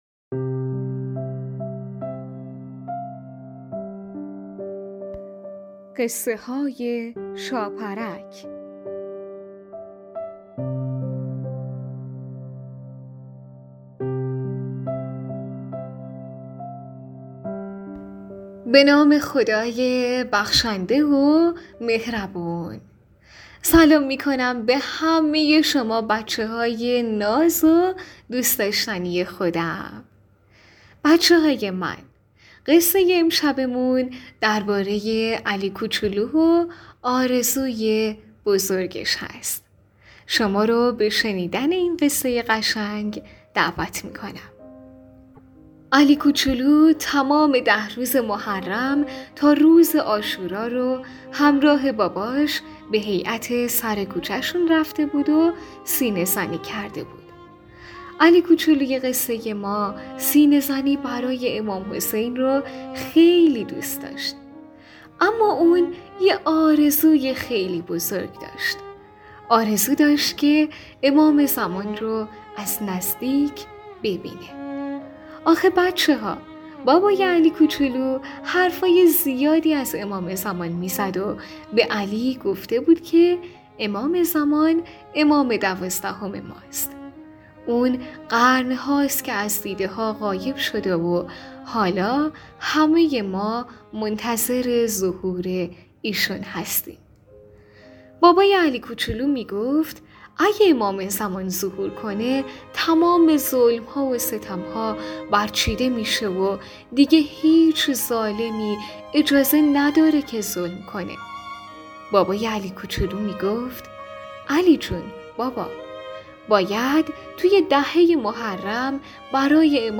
قسمت سی و هفتم برنامه رادیویی قصه های شاپرک ، داستان نمازی کودکانه مربوط به علی کوچولو و آرزوی بزرگ او برای دیدار با امام زمان علیه السلام